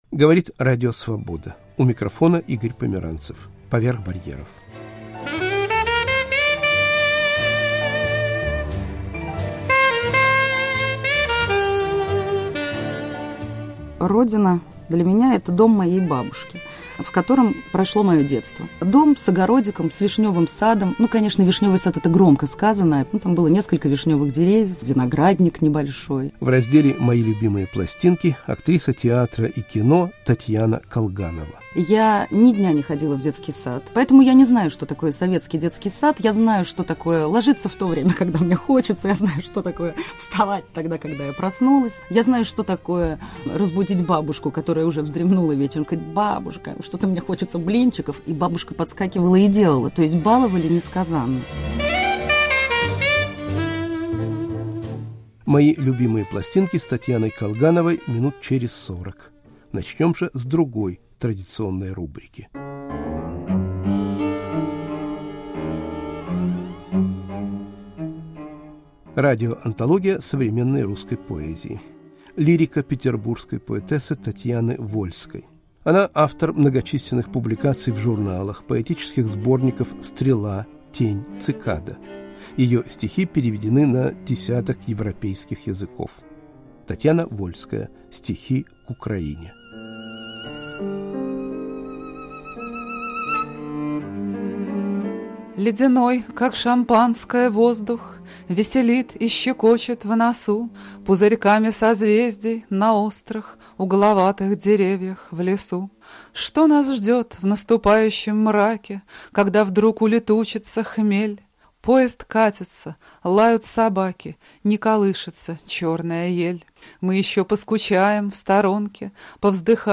Арт-допрос.